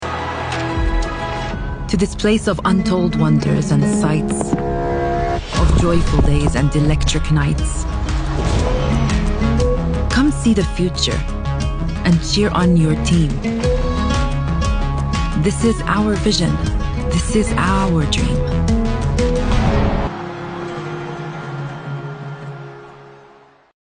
Award winning bilingual Arabic and English Voice actor with a professional home studio
Commercial